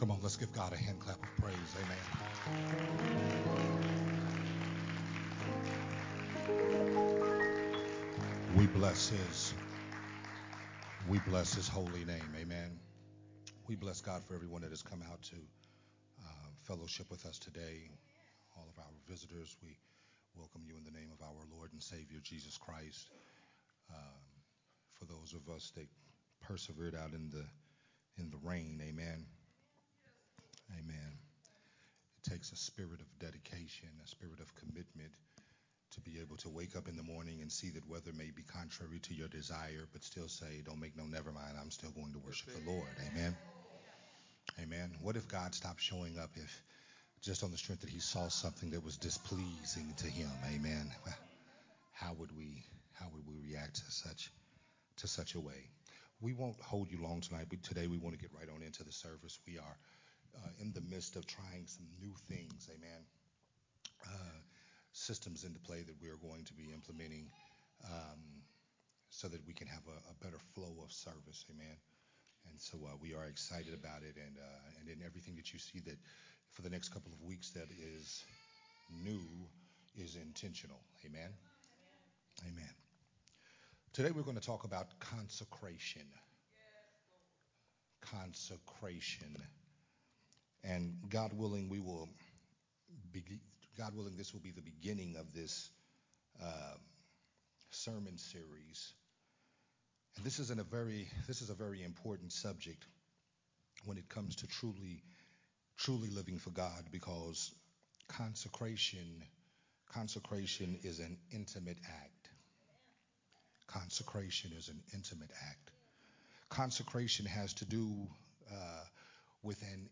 Growth Temple Ministries